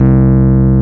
RHODES2S C2.wav